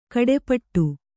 ♪ kaḍepaṭṭu